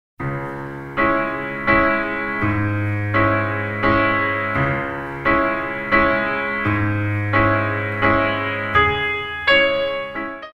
Pianist
Music is mainly selected from classical ballet repertoire.